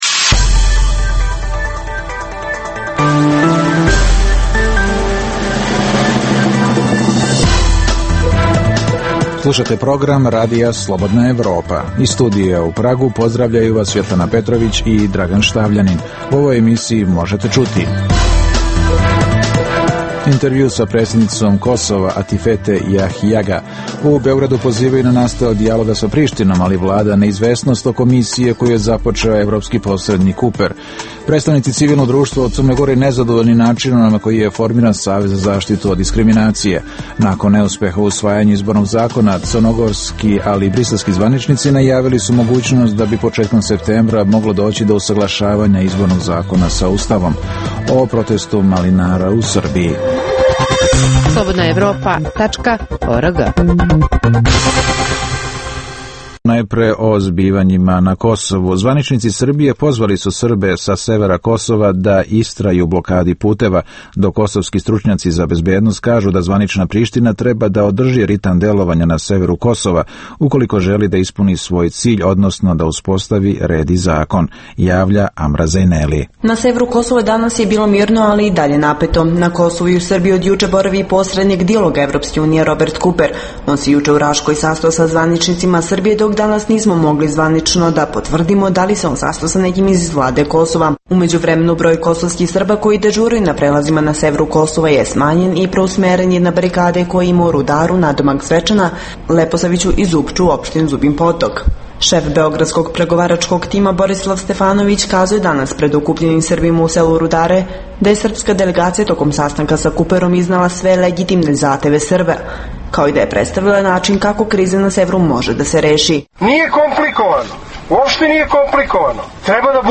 U emisiji možete čuti: - Intervju sa predsednicom Kosova Atifete Jahjaga. - U Beogradu pozivaju na nastavak dijaloga sa Prištinom, ali vlada neizvesnost oko misije koju je započeo evropski posrednik Kuper.